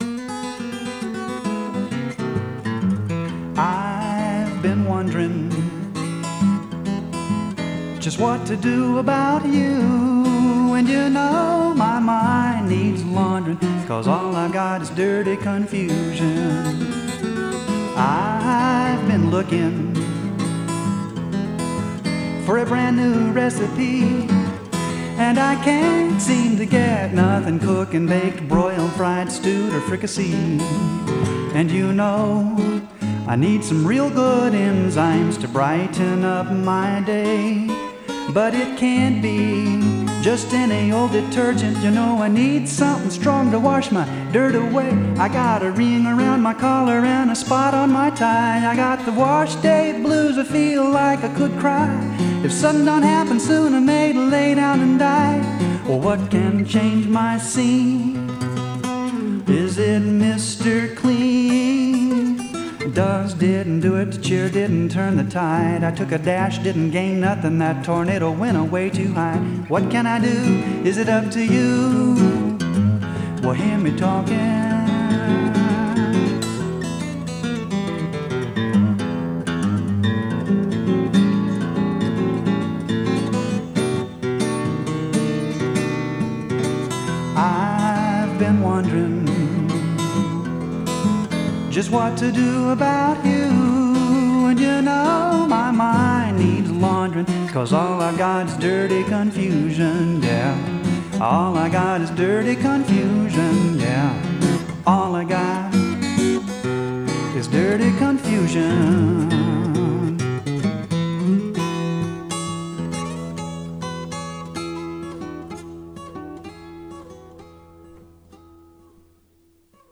One song in particular, the “Washday Blues,” expressed this ambivalence, drawing for its imagery on then popular TV ads about laundry soap. The song is addressed to Jesus, though he is never named explicitly. (You can hear an old recording of the song